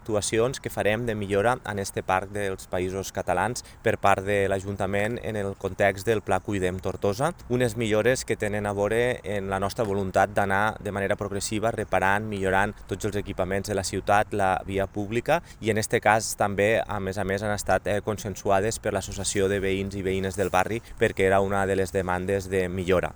L’alcalde de Tortosa, Jordi Jordan, ha destacat  que respon a l’objectiu del govern municipal de millorar la ciutat amb obres de manteniment i millora a tots els barris.